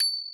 Key-rythm_ching_03.wav